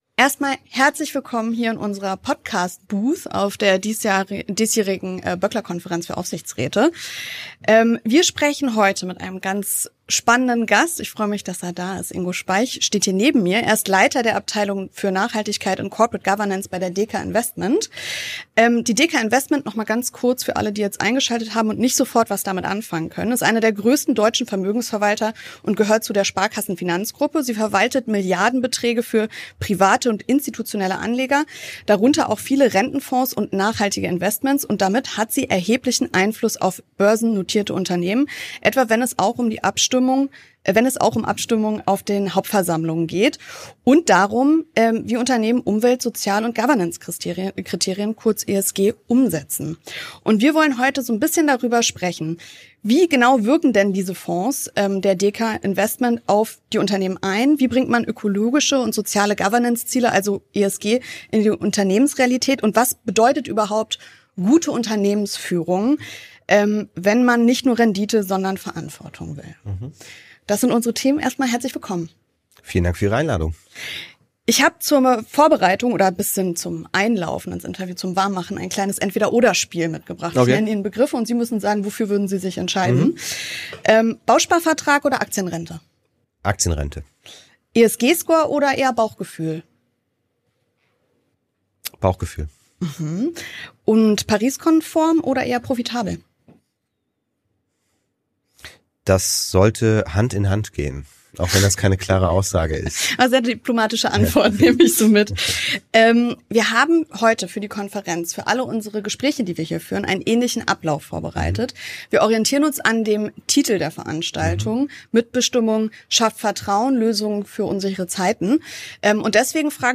Interview
Auf der Böckler Konferenz für Aufsichtsräte 2025